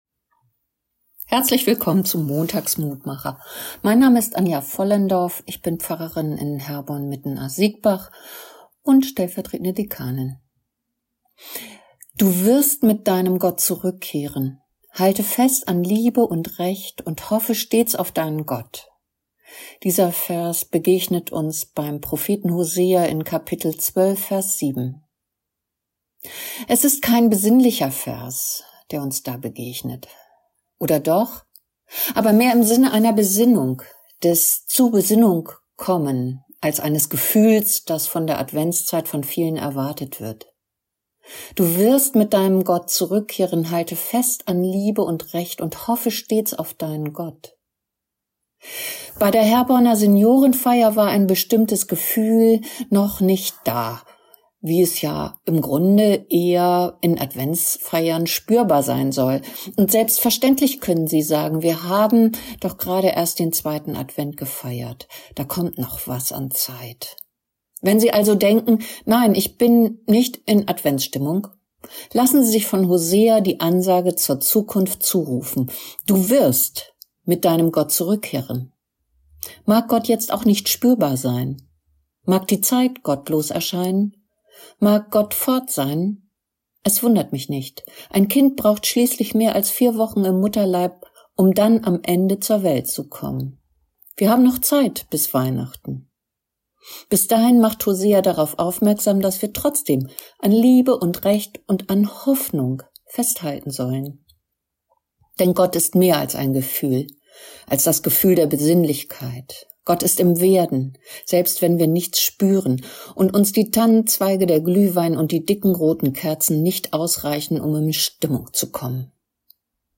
Kurzer täglicher Andachtsimpuls zu Losung oder Lehrtext des Herrnhuter Losungskalender